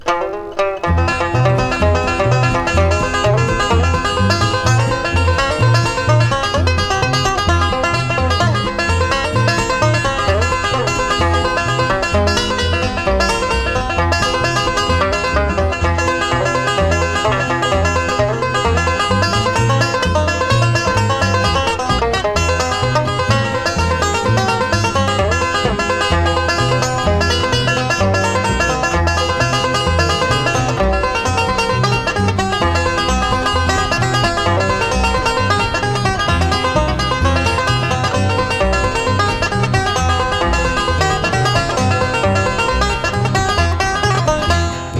Country, Bluegrass　USA　12inchレコード　33rpm　Mono